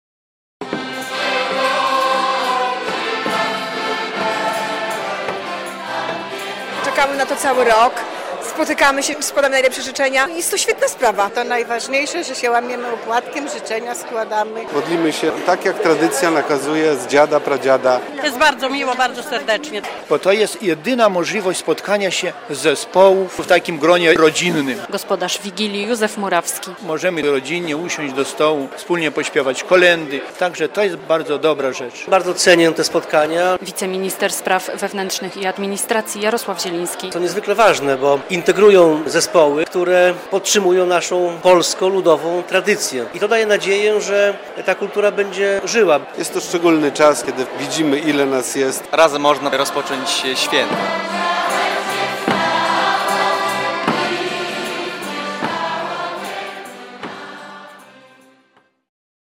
Była modlitwa, życzenia i wspólne kolędowanie. Zespoły ludowe z Suwalszczyzny spotkały się w sobotę na Wigilii Chłopskiej.